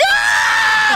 Play, download and share GOOOOOOOOOOOOO original sound button!!!!
twitch-alert-sound_97wMAVR.mp3